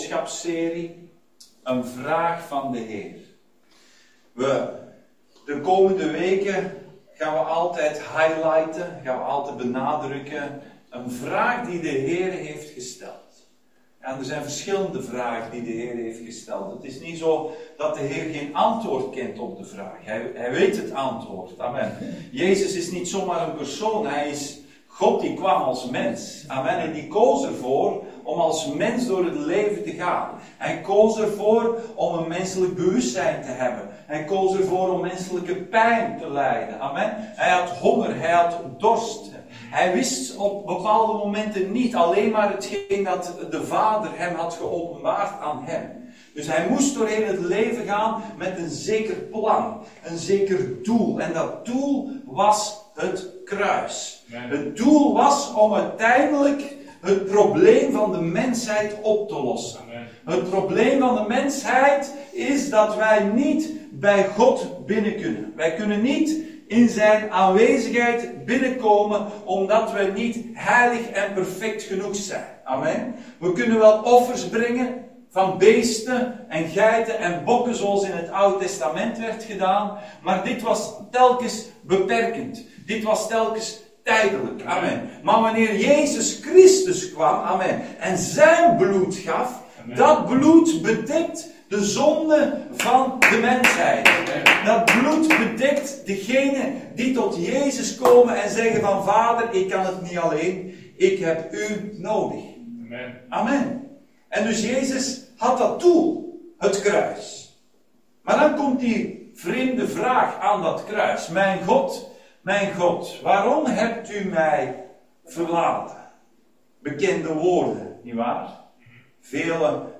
Een vraag van de Heer Dienstsoort: Zondag Dienst « Een vraag van de Heer